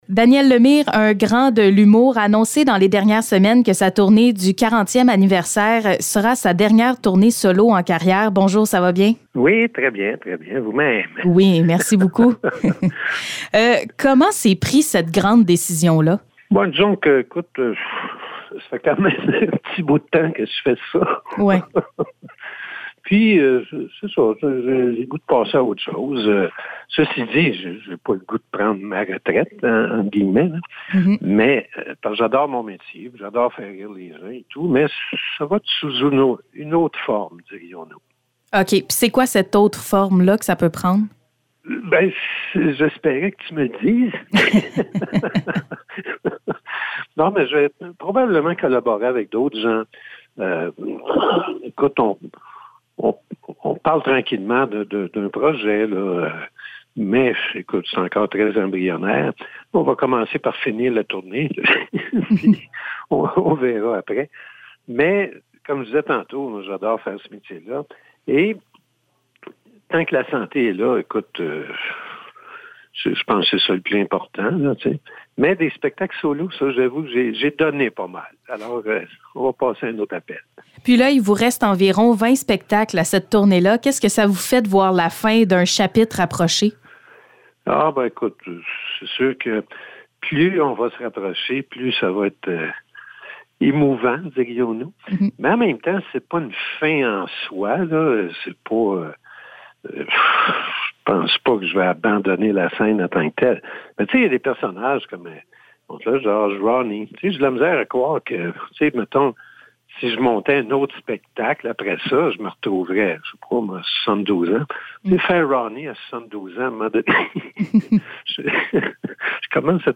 Entrevue Daniel Lemire
Entrevue avec Daniel Lemire concernant sa tournée du 40 e anniversaire qui est sa dernière tournée solo à vie.